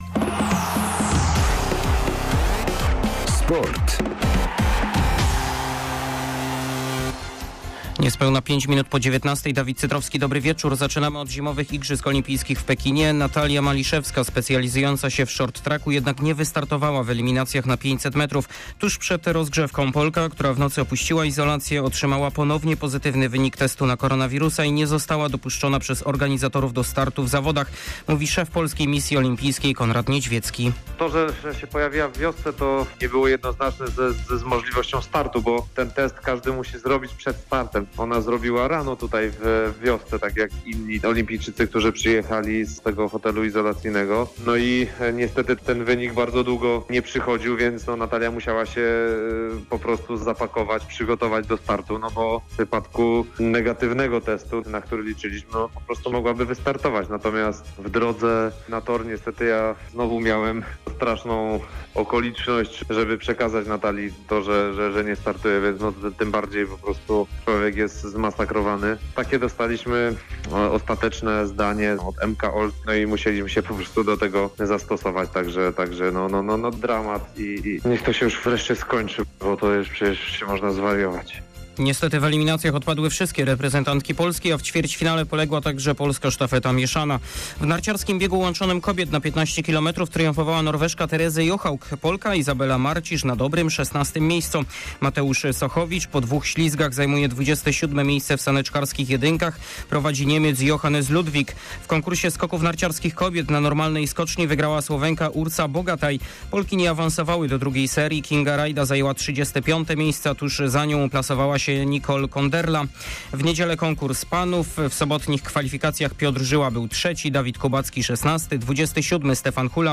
05.02.2022 SERWIS SPORTOWY GODZ. 19:05